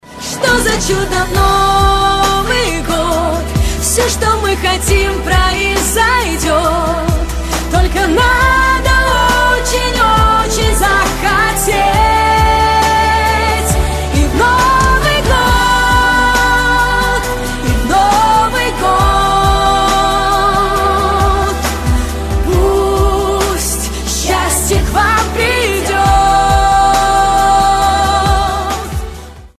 • Качество: 256, Stereo
поп